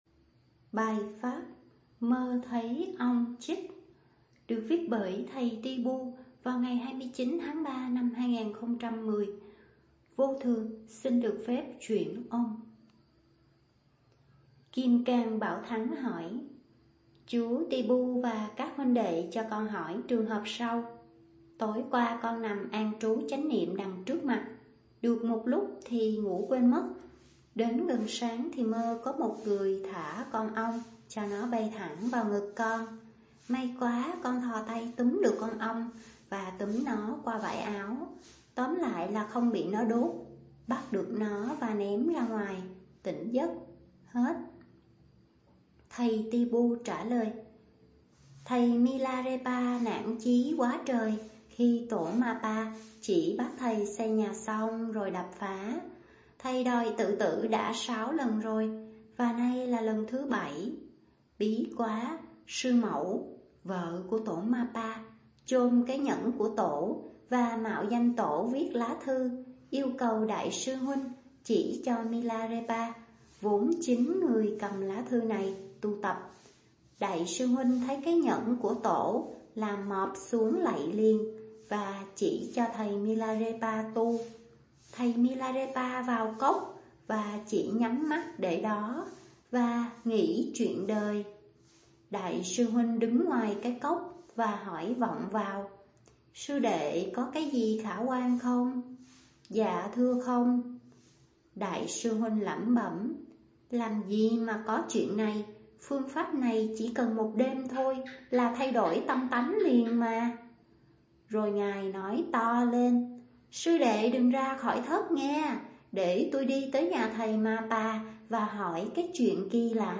chuyển âm